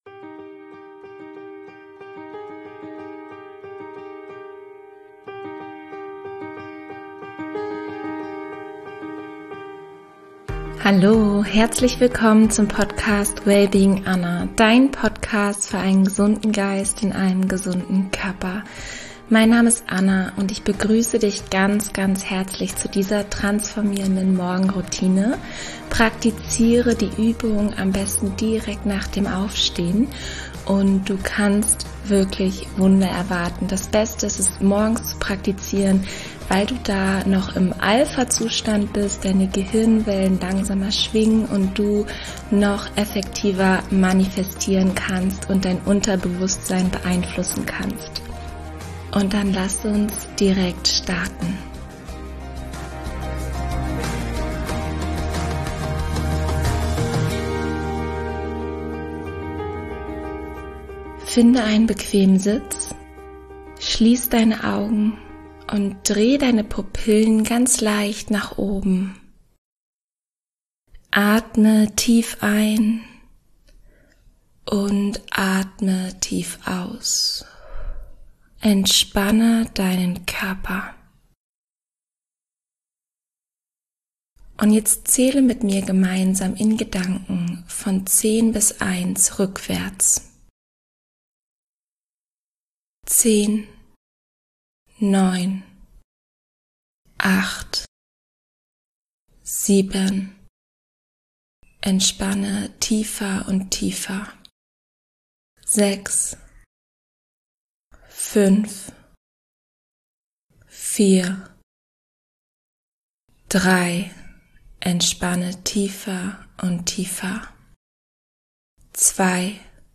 In der heutigen Episode führen wir gemeinsam eine wunderschöne, kurze und entspannende Morgenroutine durch, die dein Leben ganzheitlich transformieren kann: für mehr Fülle, Gesundheit, Wohlstand und Erfolg.